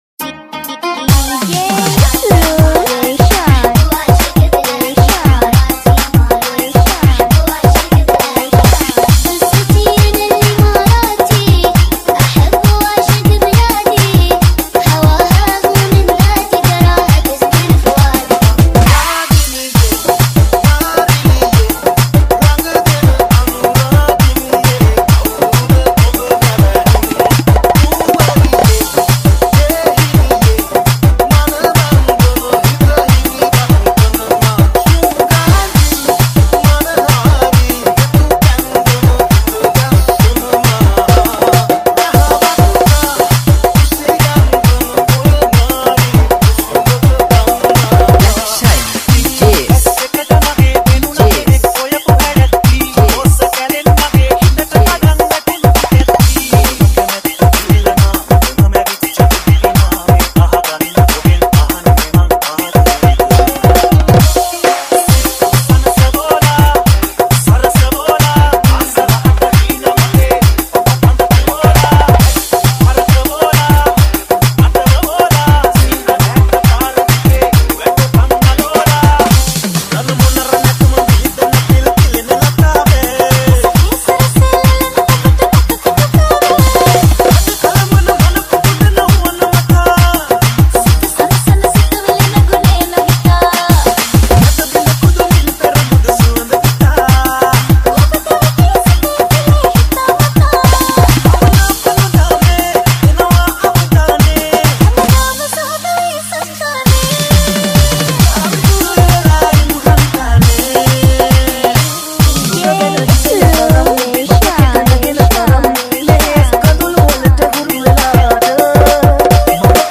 Remix
Genre - 6-8